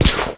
Minecraft Version Minecraft Version snapshot Latest Release | Latest Snapshot snapshot / assets / minecraft / sounds / item / armor / damage_wolf4.ogg Compare With Compare With Latest Release | Latest Snapshot
damage_wolf4.ogg